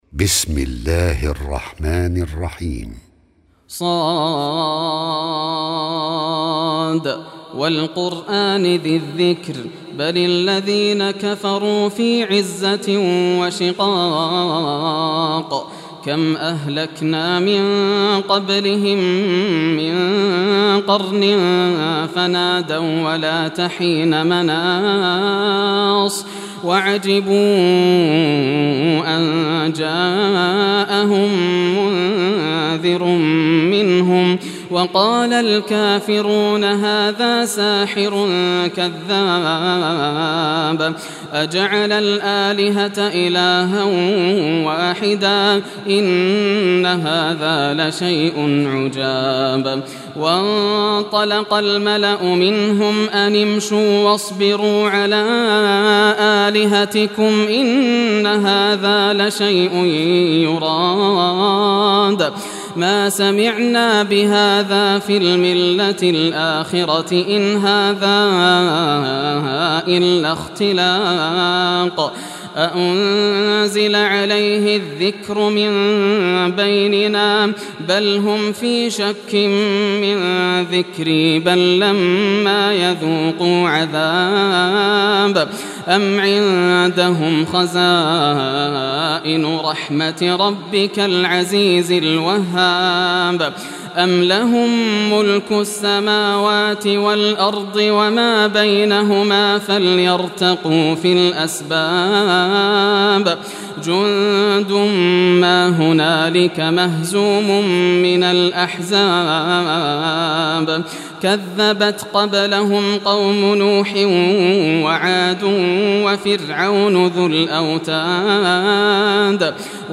Surah Sad Recitation by Yasser al Dosari
Surah Sad, listen or play online mp3 tilawat / recitation in Arabic in the beautiful voice of Sheikh Yasser al Dosari.
38-surah-sad.mp3